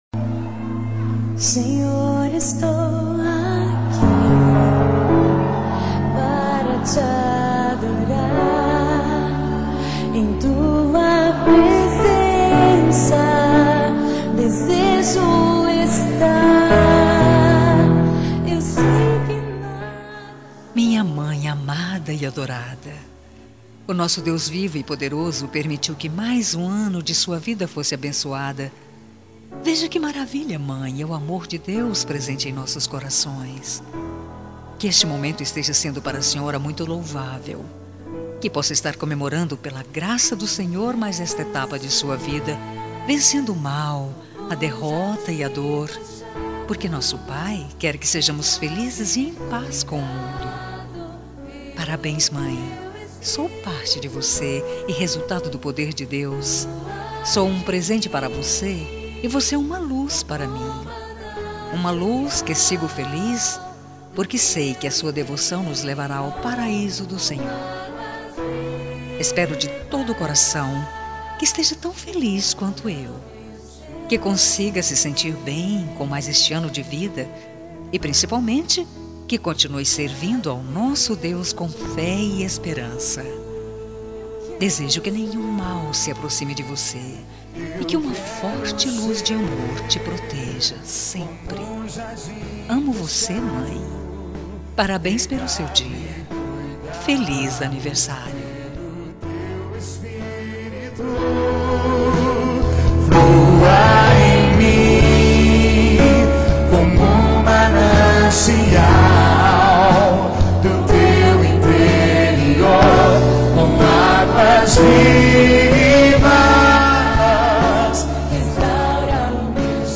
Telemensagem Evangélica Anversário Mãe | Com Reação e Recado Grátis